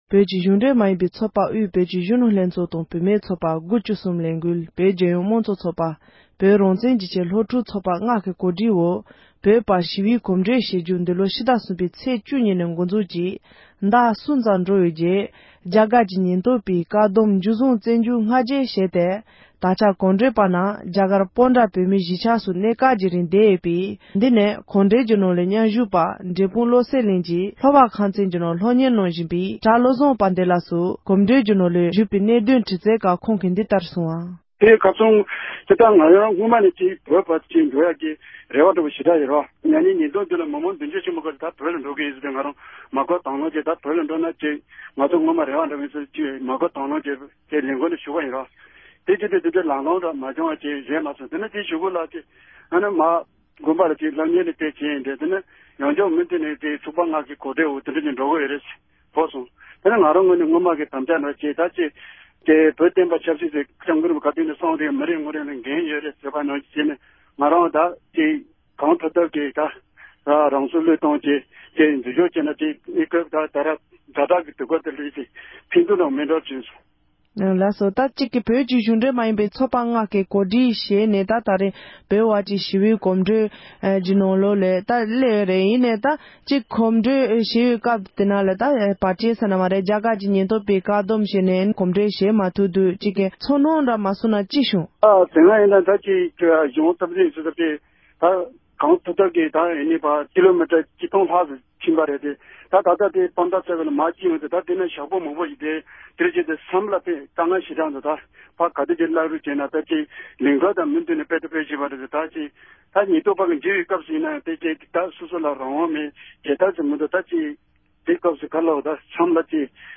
འབྲེལ་ཡོད་མི་སྣ་ལ་བཀའ་འདྲི་ཞུས་པ་ཞིག་གསན་རོགས་གནང༌༎
སྒྲ་ལྡན་གསར་འགྱུར།